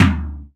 07_Perc_20_SP.wav